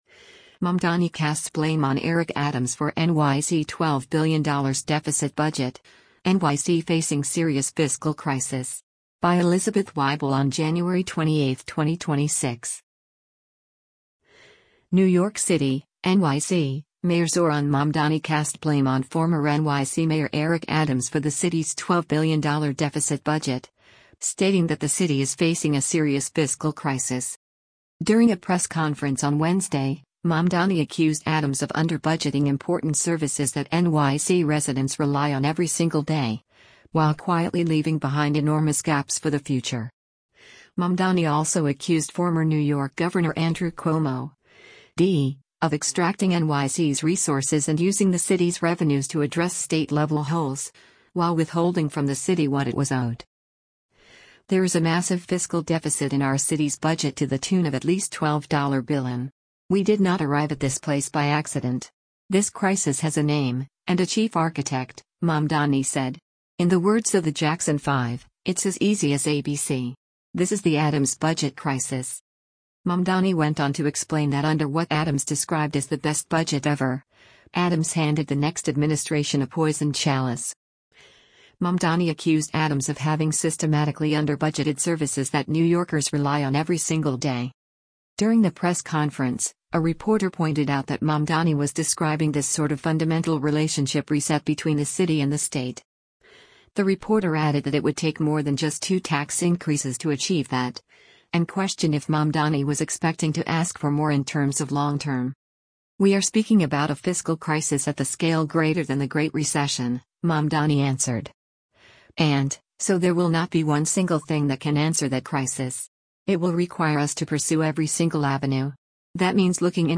Zohran Mamdani, Democratic nominee for New York City Mayor, speaks during a news conferenc